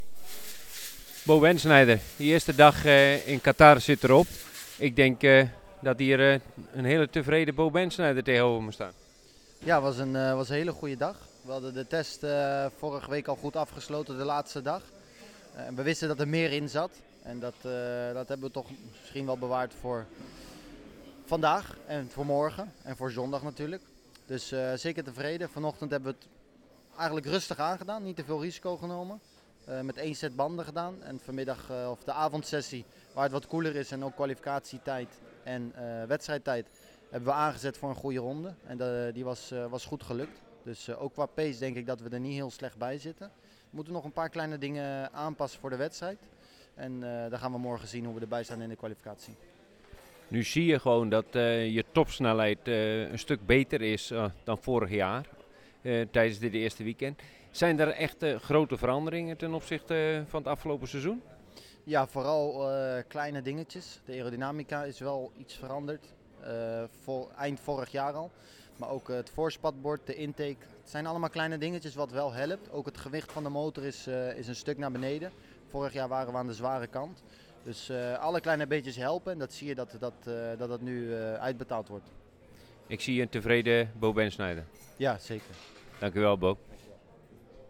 Luister in onderstaand audiofragment naar de reactie van Bo na de vrijdag op het Losail International Circuit.